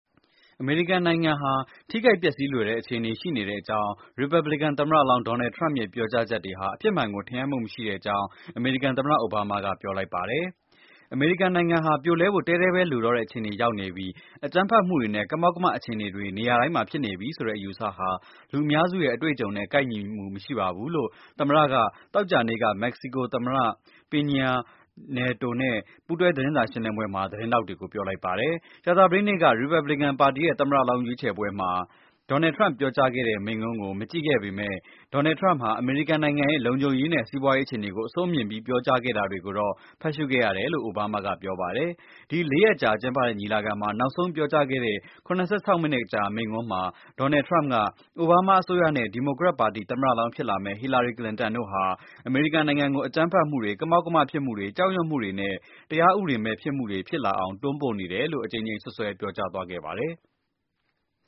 မက္ကဆီကို သမ္မတ Peña Nieto နဲ့ ကန်သမ္မတ အိုဘားမားတို့ ပူးတွဲ သတင်းစာ ရှင်းလင်းပွဲ ( ဇူလိုင် ၂၂၊ ၂၀၁၆)